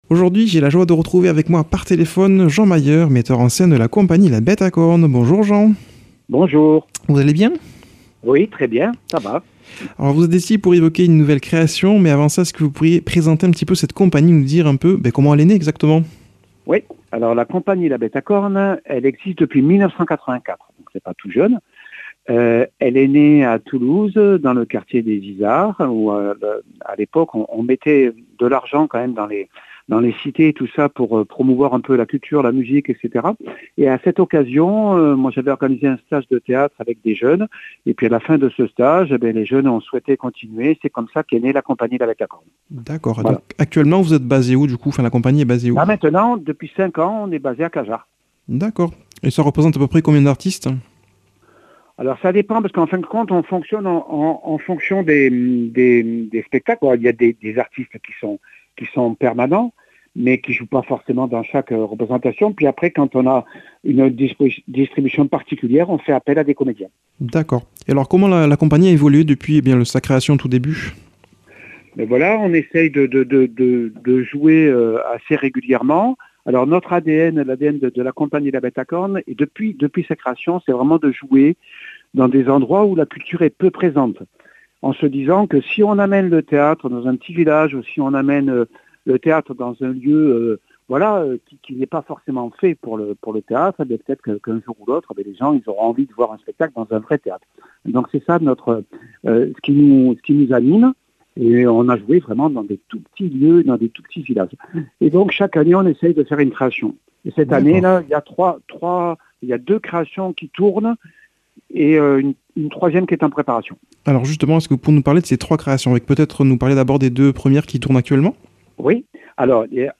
Une émission présentée par
Présentateur